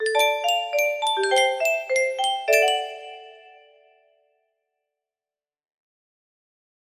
Just the piano riff from the beginning